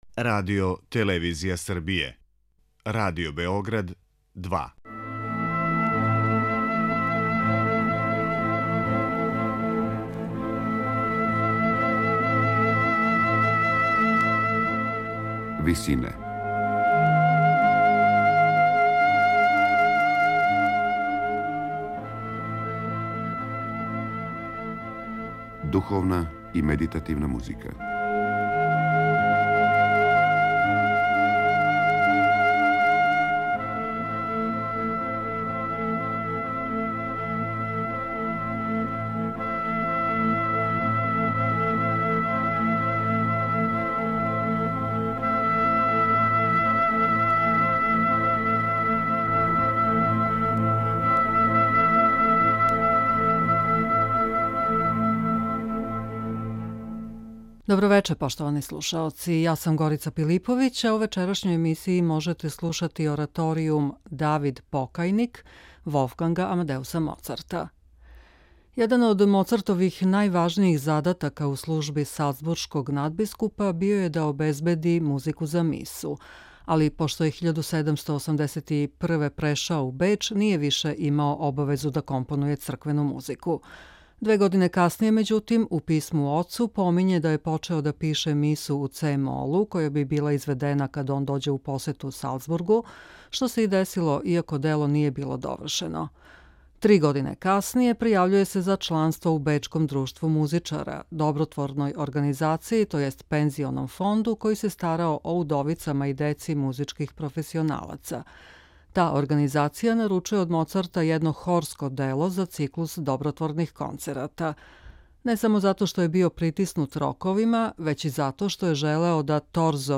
У вечерашњој емисији Висине можете слушати ораторијум „Давид-покајник” Волфганга Амадеуса Моцарта.